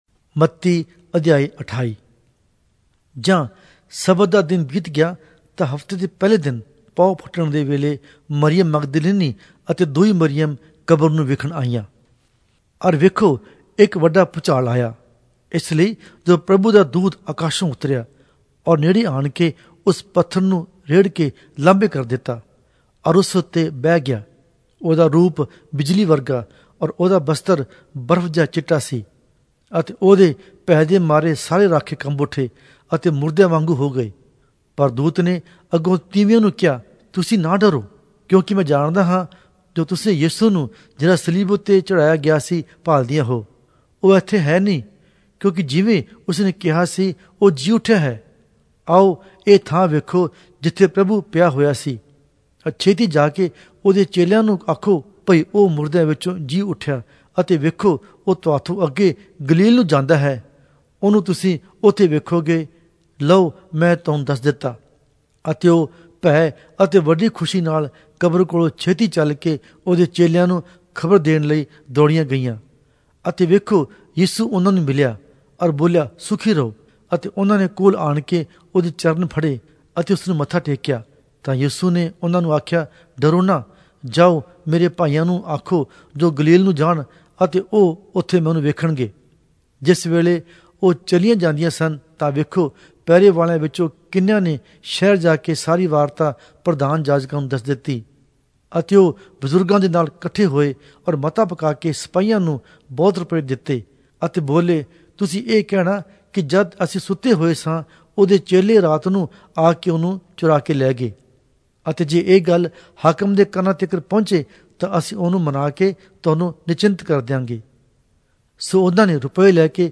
Punjabi Audio Bible - Matthew 11 in Bnv bible version